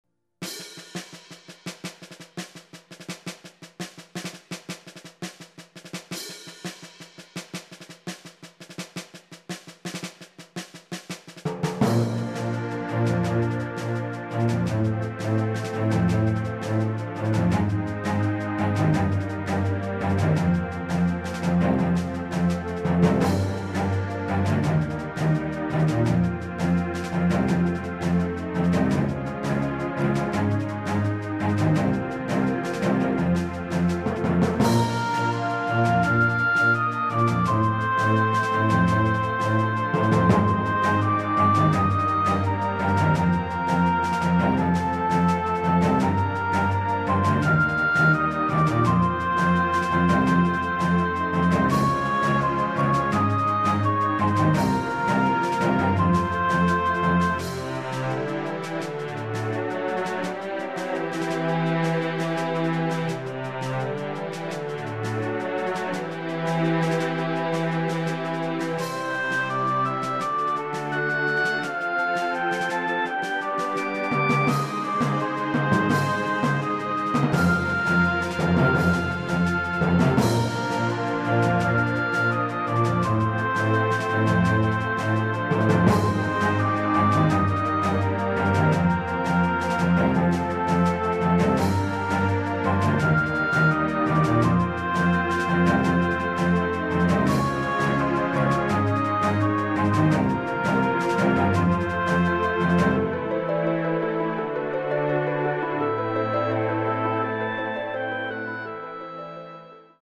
[and that music sounds like adventure.]